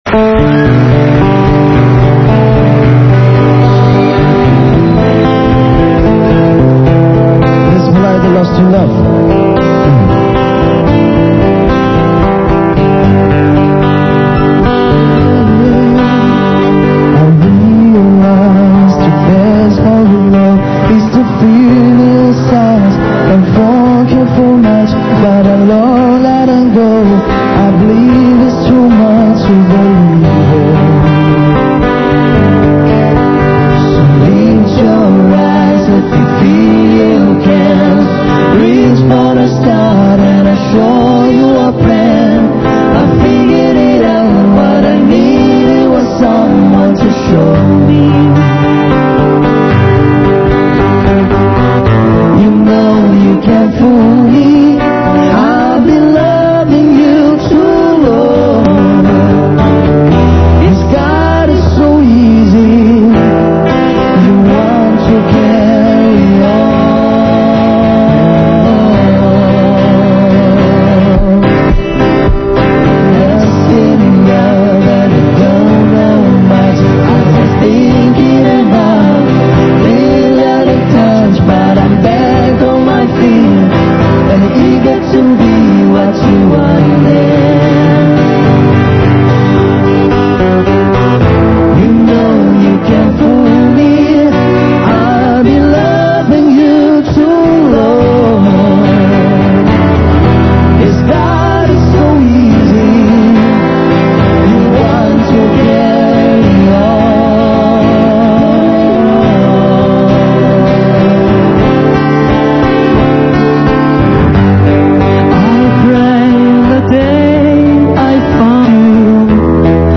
Sing English songs